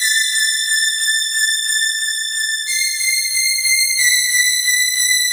Synth 32.wav